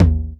D2 TOM-30.wav